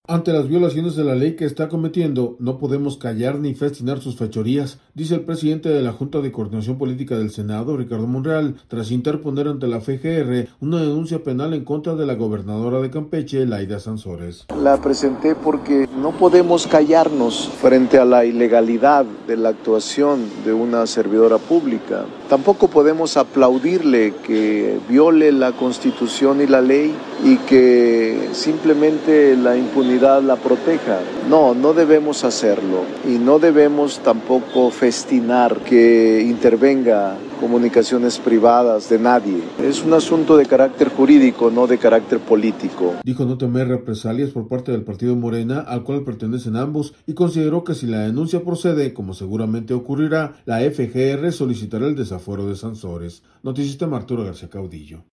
audio Ante las violaciones a la Ley que está cometiendo, no podemos callar ni festinar sus fechorías, dice el presidente de la Junta de Coordinación Política del Senado, Ricardo Monreal, tras interponer ante la FGR, una denuncia penal en contra de la gobernadora de Campeche, Layda Sansores.